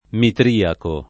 vai all'elenco alfabetico delle voci ingrandisci il carattere 100% rimpicciolisci il carattere stampa invia tramite posta elettronica codividi su Facebook mitriaco [ mitr & ako ] (meno com. mitraico ) agg.; pl. m. ‑ci